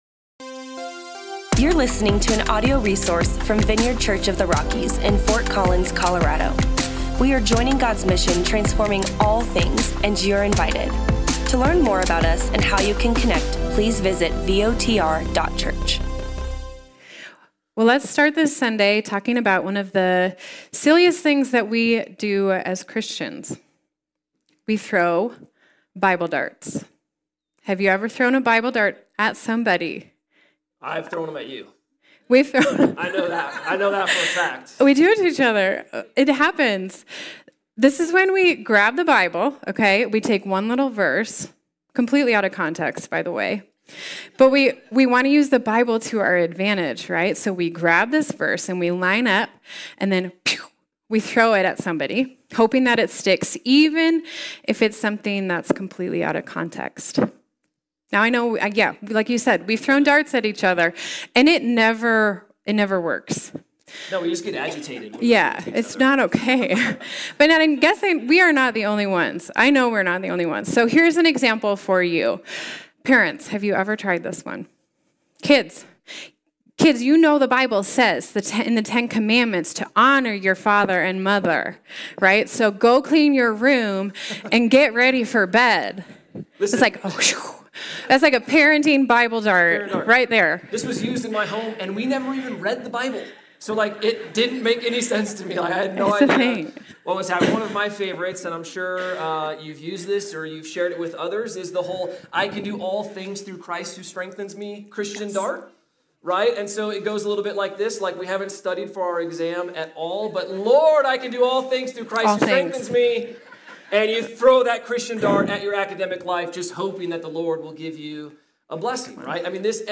In Ephesians 5, we learn that marriage mysteriously points to the love Christ has for the church and when done well, marriage can be part of your ministry. This sermon will discuss the incredible value Christ placed on women and marriage, as well as share some practical tips on what this looks like in our everyday lives.